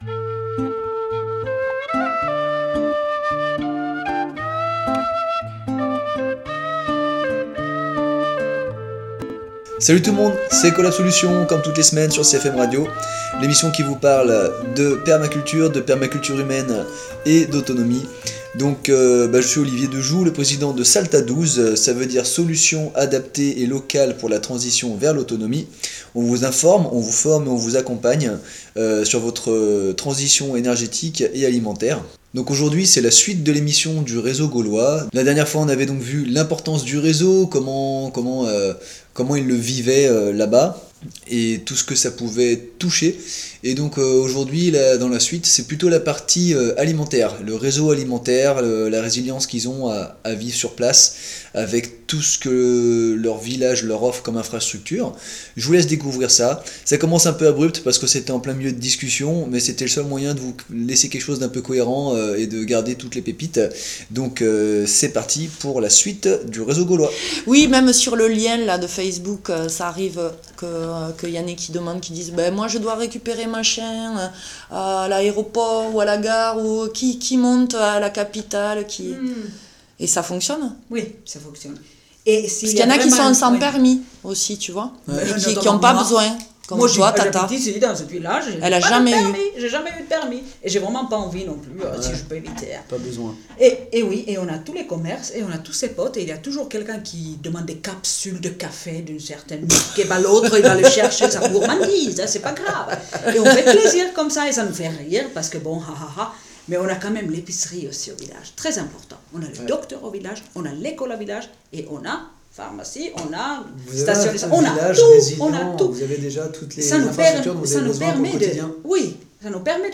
Suite de l’interview sur l’importance du réseau vécu et illustrer par ce groupe d’amis très soudés. Cette 2ème partie est plus ciblé sur les réseaux d’alimentation et plus encore !